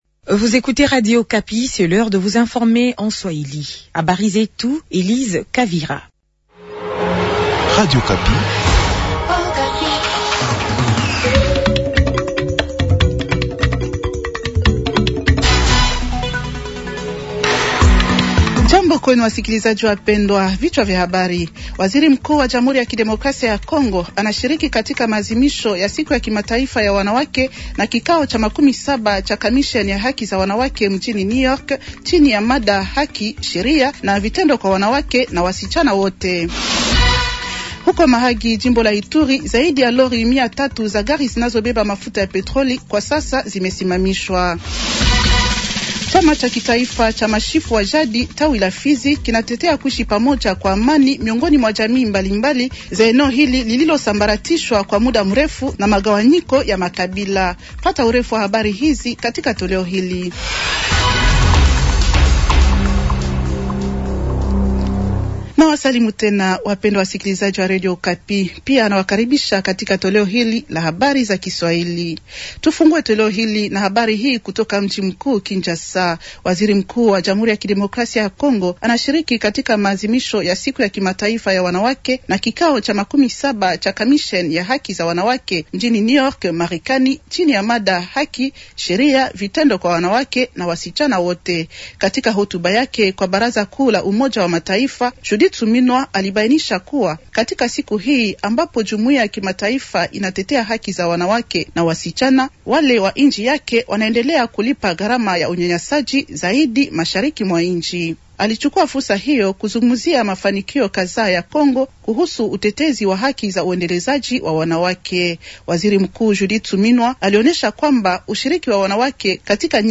Journal swahili de lundi soir 100326